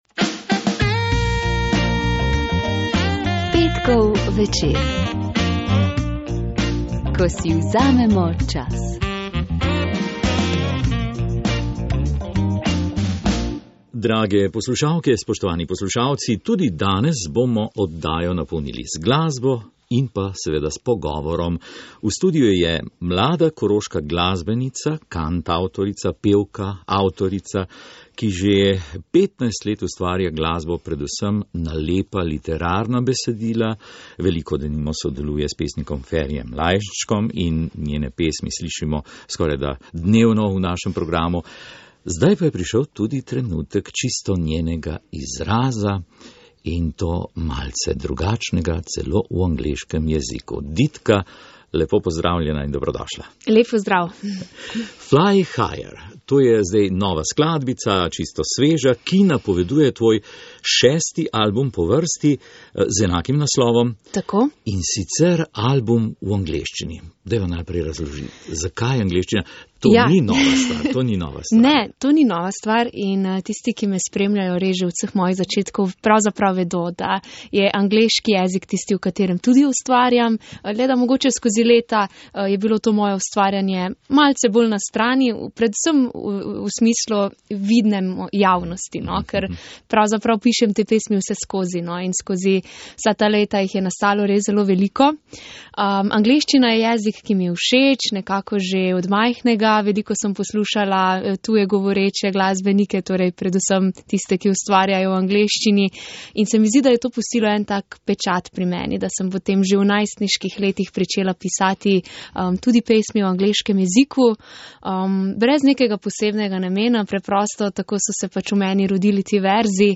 Oddaja Petkov večer je gostila tri eksplozivne gostje - Dinamitke.